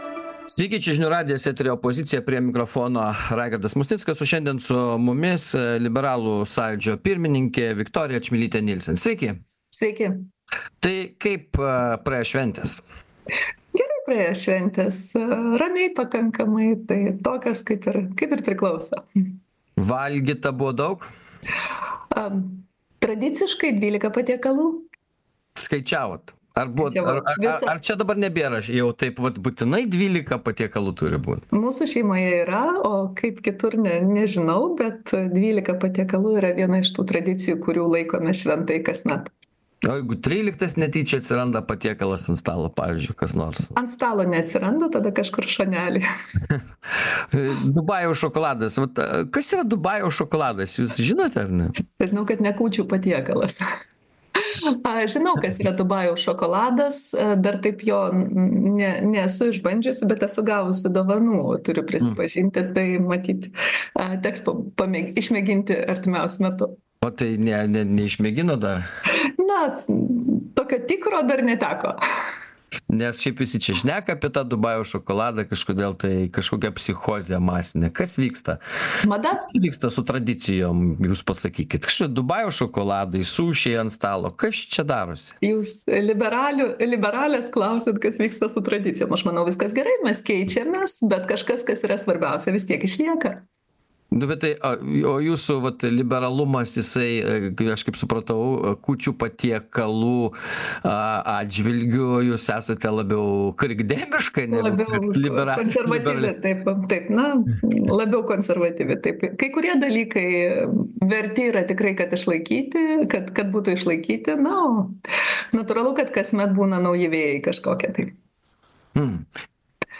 Laidoje dalyvauja Lietuvos liberalų sąjūdžio vadovė Viktorija Čmilytė-Nielsen.